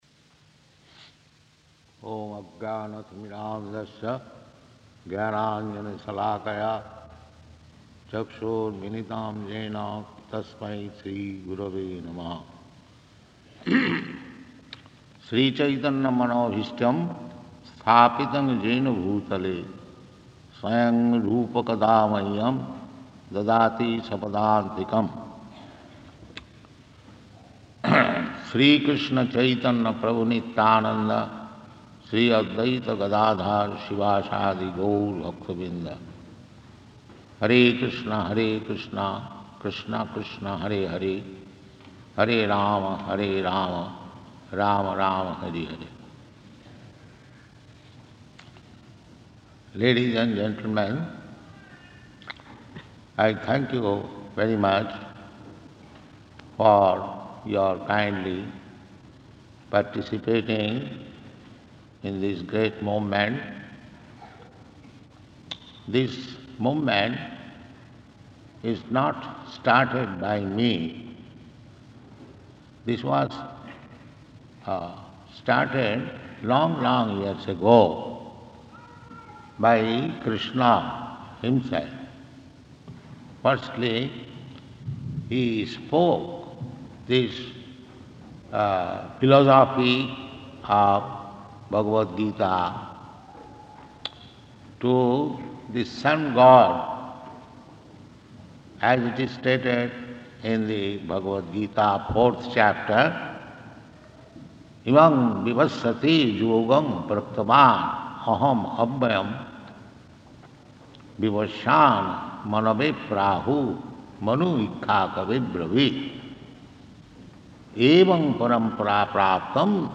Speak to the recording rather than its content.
City Hall Lecture Location: Durban